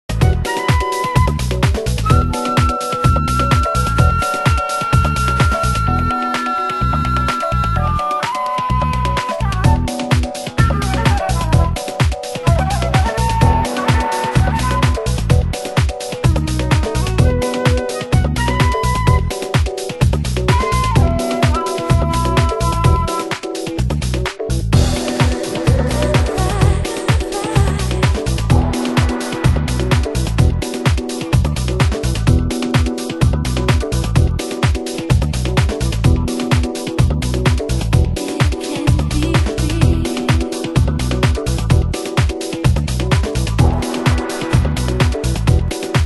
ミニマルなシンセのループ、艶のある女性ヴォーカルが展開する歌モノ！